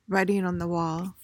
PRONUNCIATION:
(RY-ting ahn thuh WAWL)